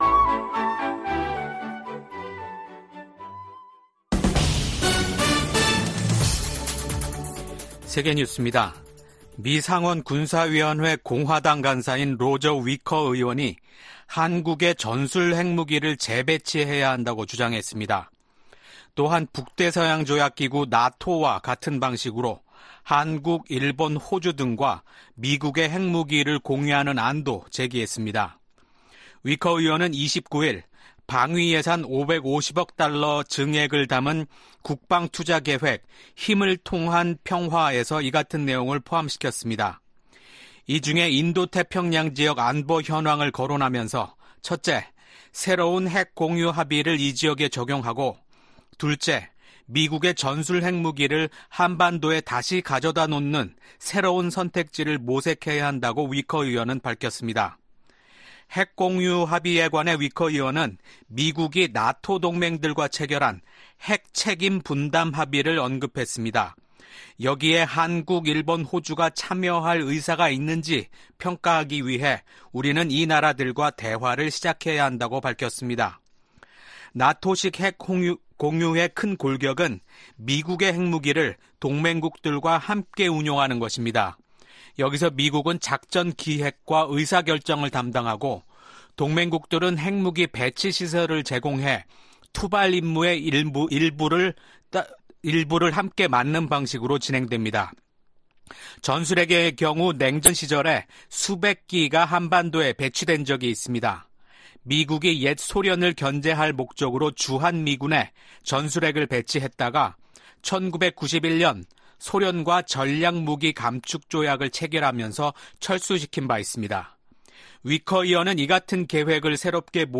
VOA 한국어 아침 뉴스 프로그램 '워싱턴 뉴스 광장' 2024년 5월 31일 방송입니다. 북한이 30일, 동해상으로 단거리 탄도미사일 10여발을 발사했습니다.